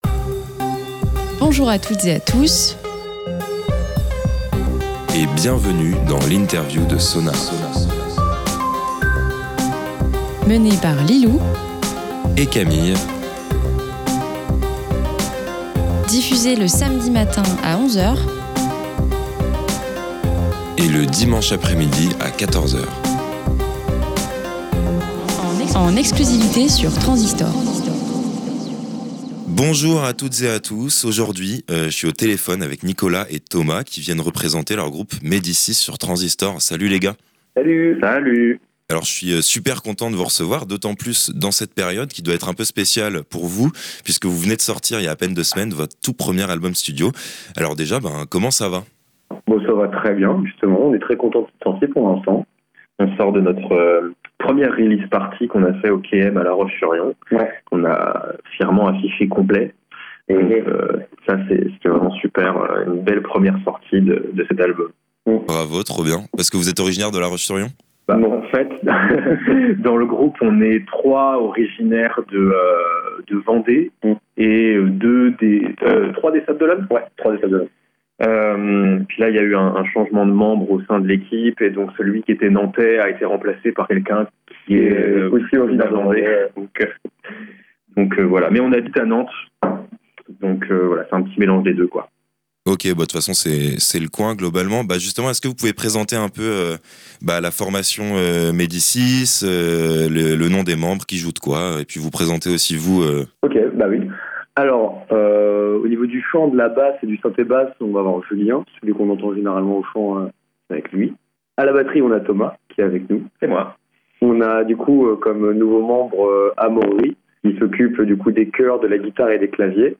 Malheureusement ils n'ont pas pu faire le déplacement jusqu'au Faou (mais on leur en veut pas), alors il faudra se contenter de cet entretien par téléphone, qui s'est avéré fort sympathique, et m'a permis de mieux cerner leur univers musical et comprendre l'histoire de ce premier album.